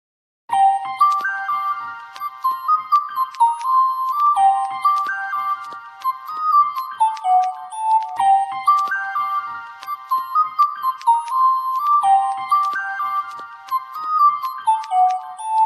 country banjo dueling